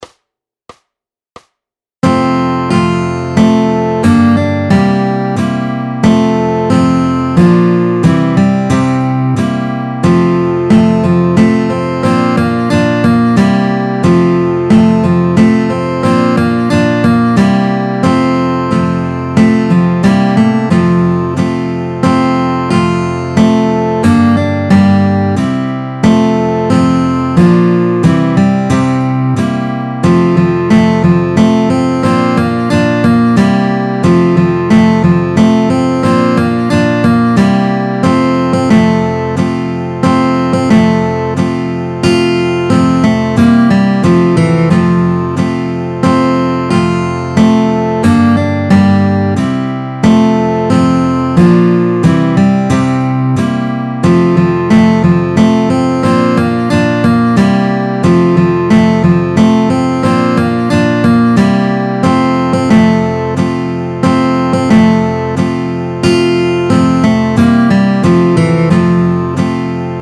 Formát Kytarové album
Hudební žánr Vánoční písně, koledy